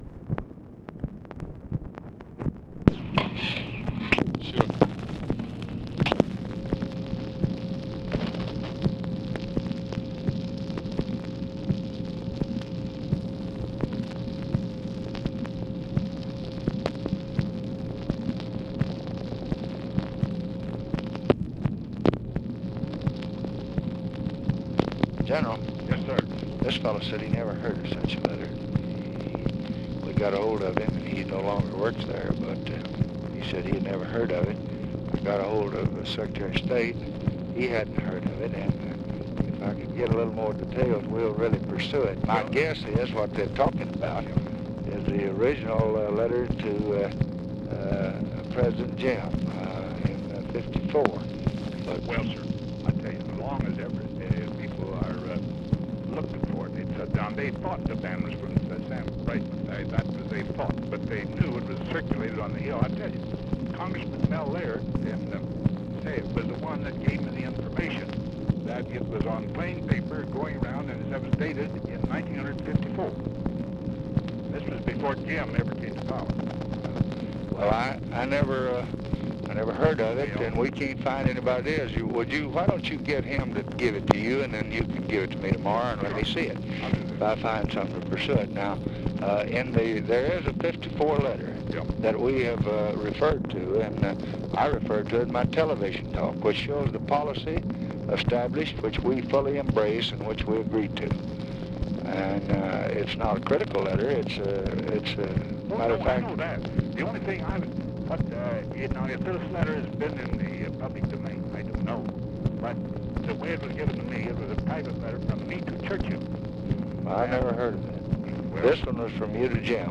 Conversation with DWIGHT EISENHOWER, June 29, 1965
Secret White House Tapes